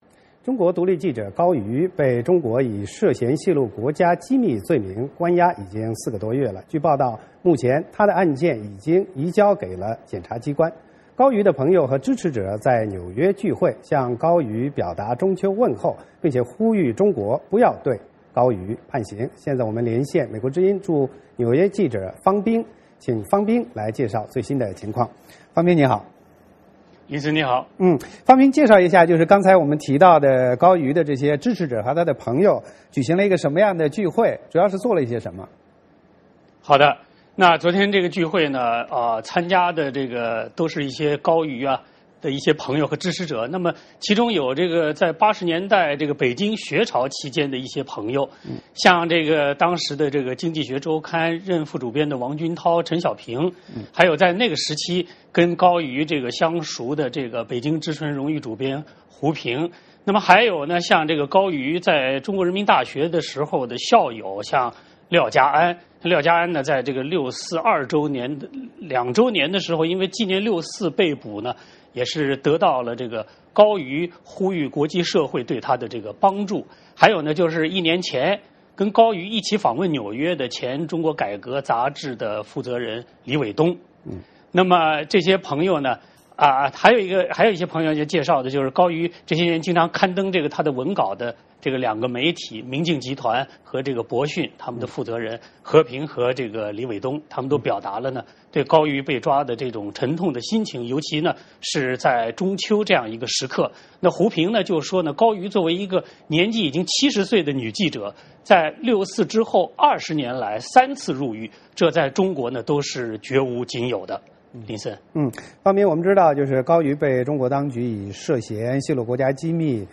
VOA连线：纽约活动人士关注高瑜案